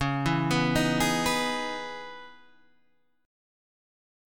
Dbm9 Chord
Listen to Dbm9 strummed